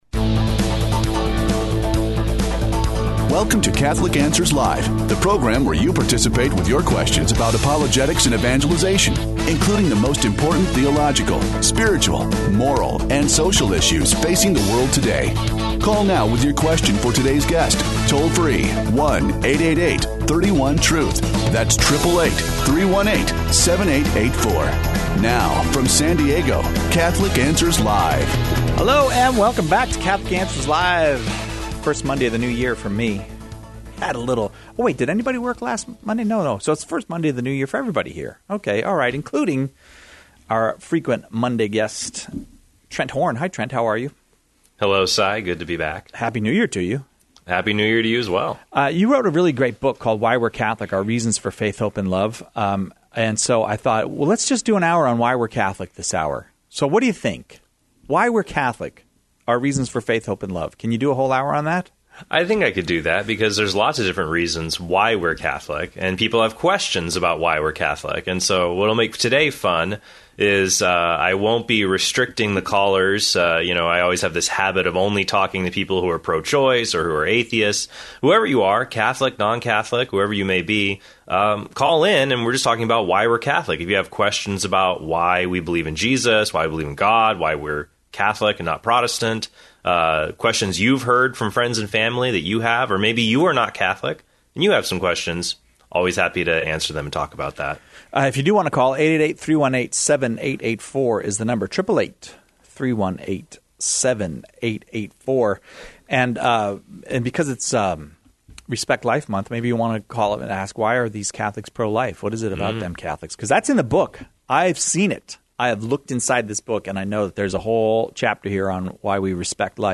helping callers grasp why we believe what we believe on a range of topics.